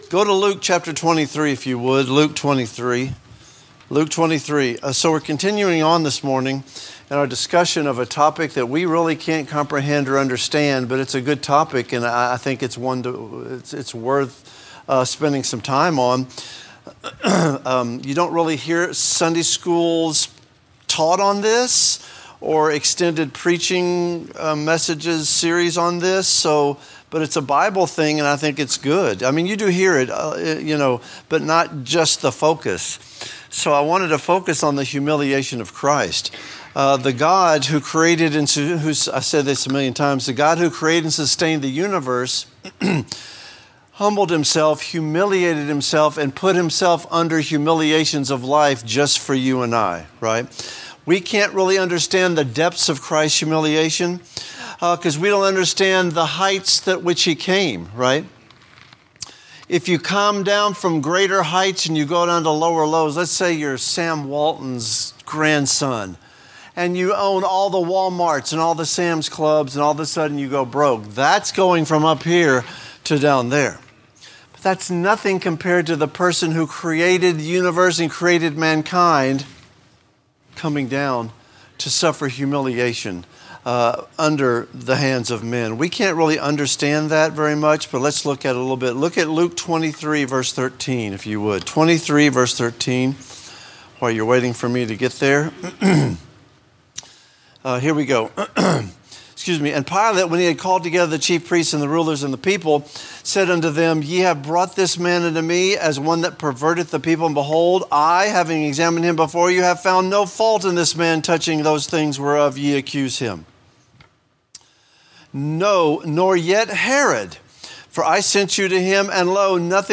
Related Topics: Teaching